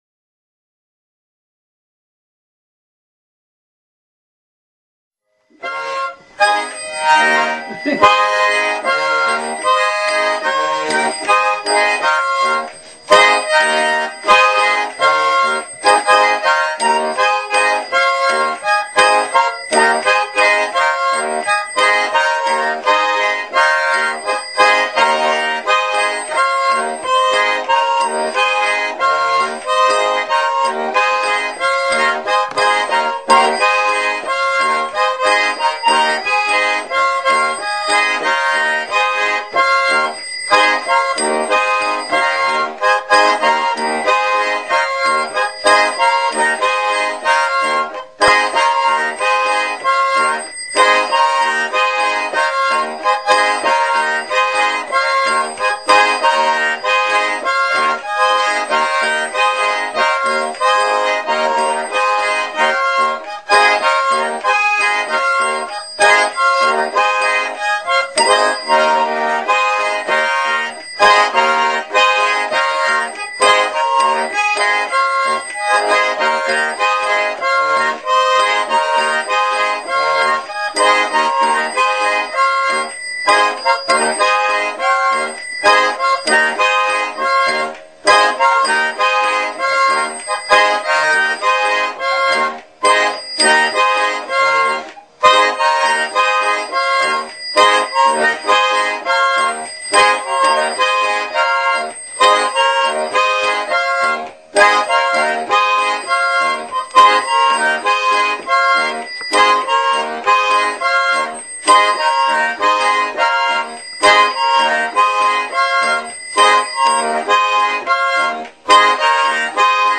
Саратовская гармошка: Традиционный Наигрыш